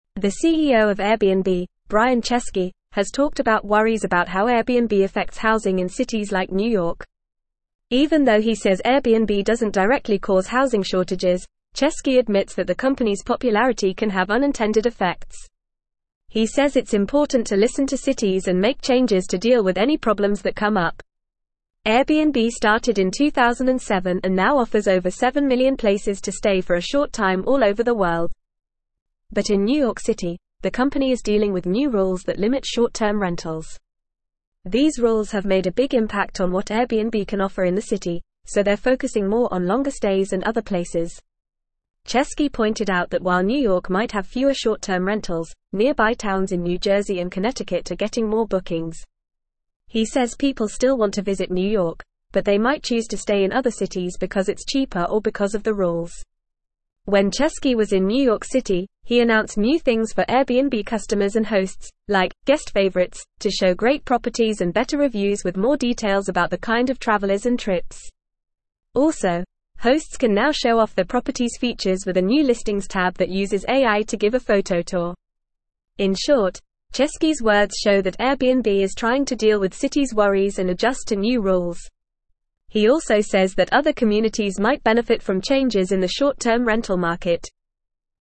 Fast
English-Newsroom-Upper-Intermediate-FAST-Reading-Airbnb-CEO-Addresses-Housing-Concerns-Adapts-to-Regulations.mp3